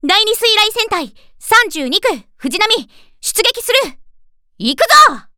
Ship_Voice_Fujinami_Kai_Ni_Joining_A_Fleet.mp3